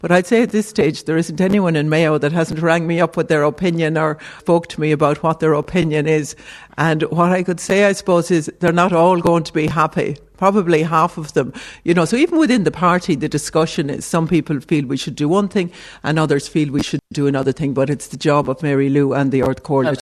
Mayo Sinn Fein TD Rose Conway Walsh, who has also been mentioned as a prospective candidate, wouldn’t be drawn on the party’s intentions……